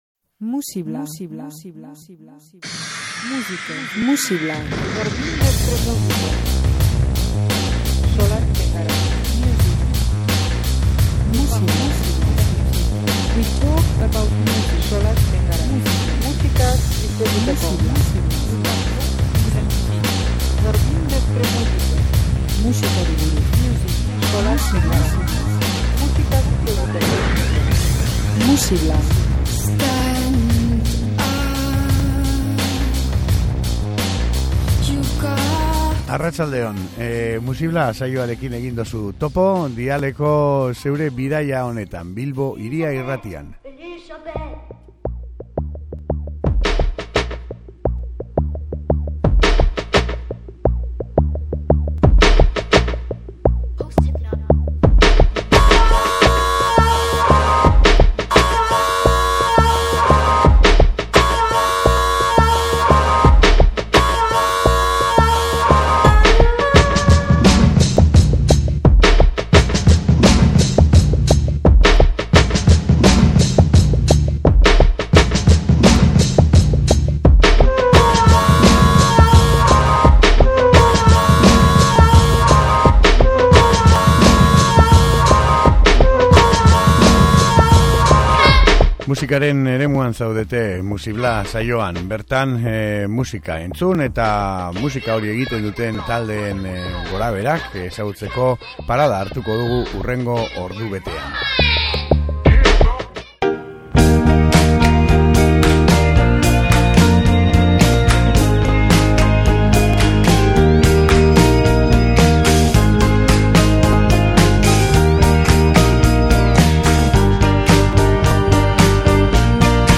Askotariko musika gaurko saioan